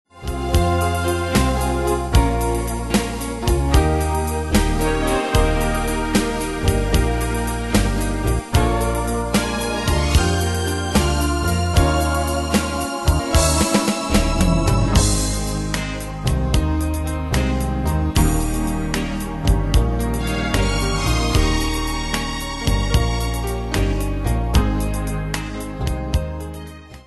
Style: Oldies Ane/Year: 1959 Tempo: 75 Durée/Time: 2.39
Danse/Dance: Ballade Cat Id.
Pro Backing Tracks